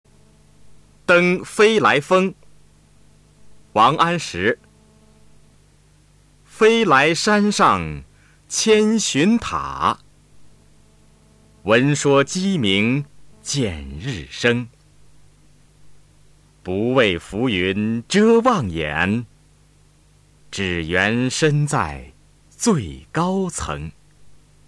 《登飞来峰》mp3朗读